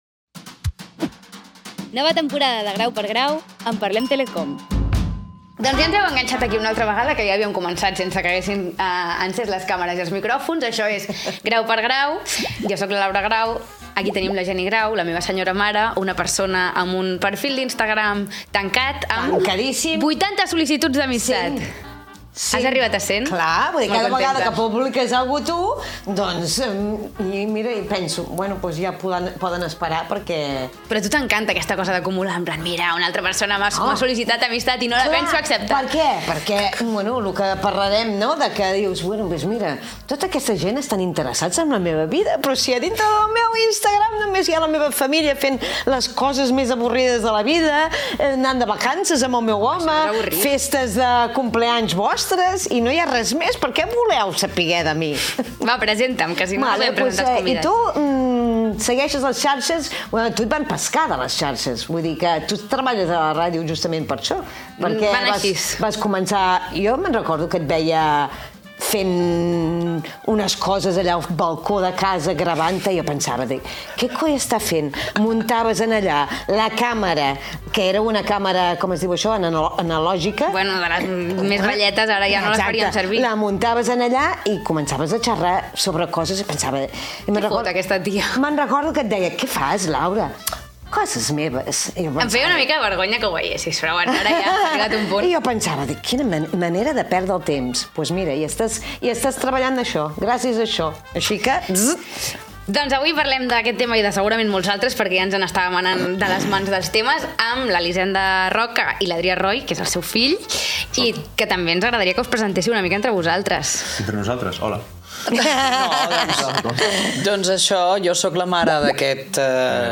Publicitat, conversa inicial sobre Instagram